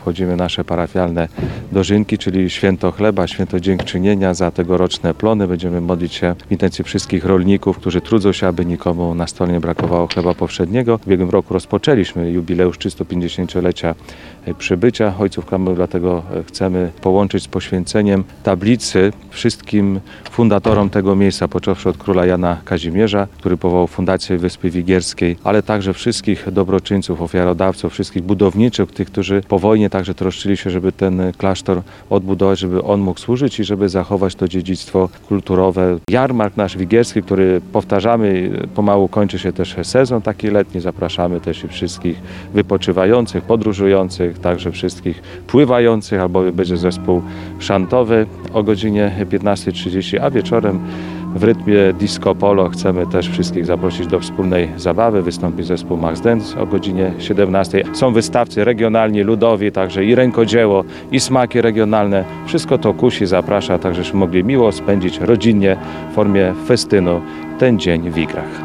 Dożynki Parafialne odbywają się w niedzielę (25.08) w pokamedulskim klasztorze nad Wigrami.